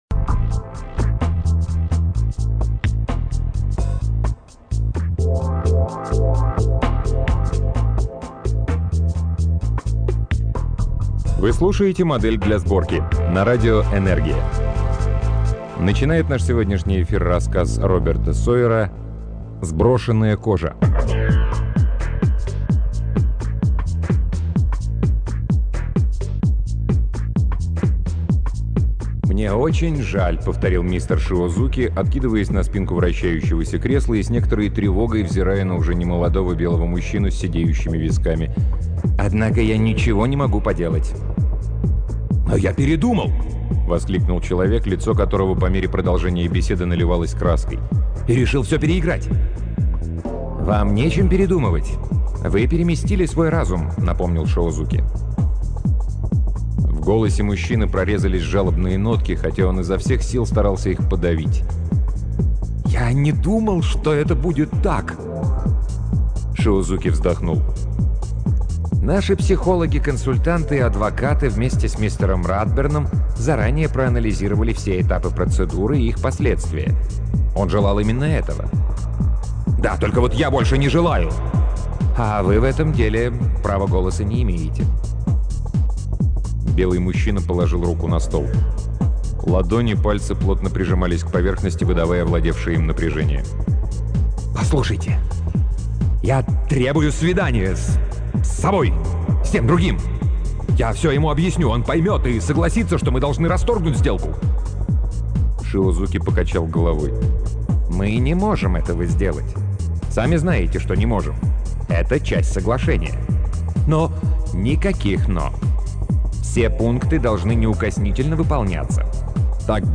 Аудиокнига Роберт Сойер — Сброшенная кожа
Аудиокниги передачи «Модель для сборки» онлайн